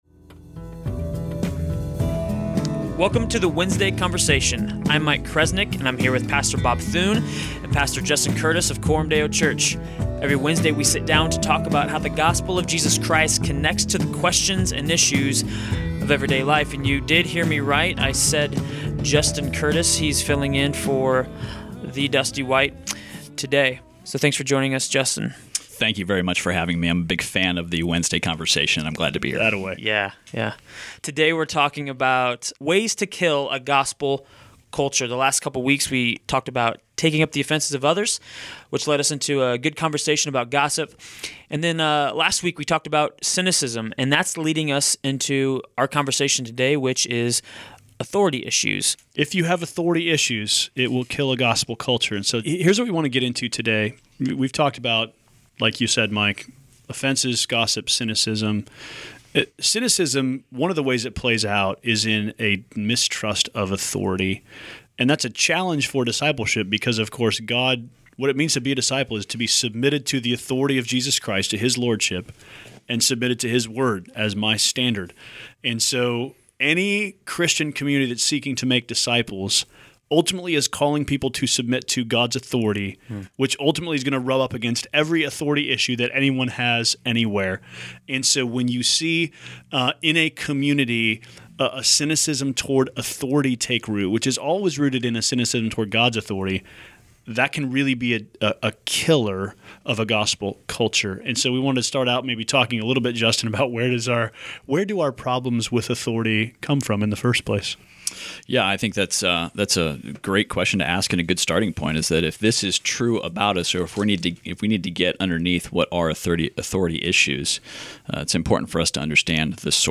A series of conversations on Ways to Kill a Gospel Culture. Today we talked about "Authority Issues."